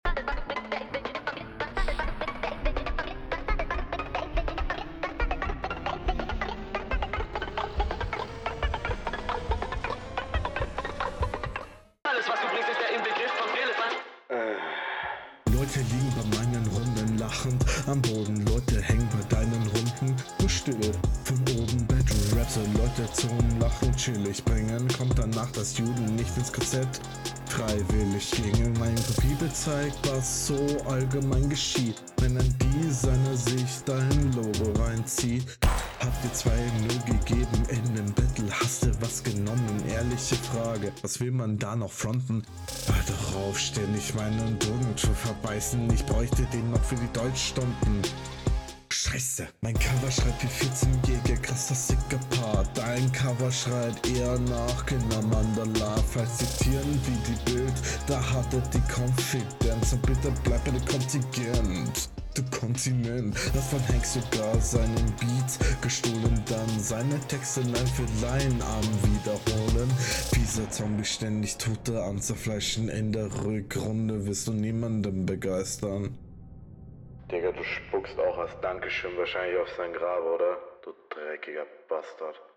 Kommt mir sehr leise und ungeil gemixt vor.